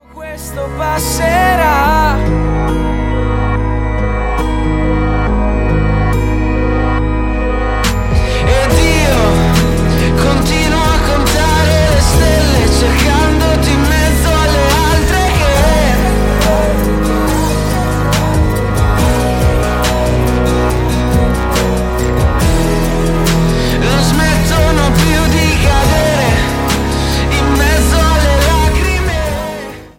POP  (03.56)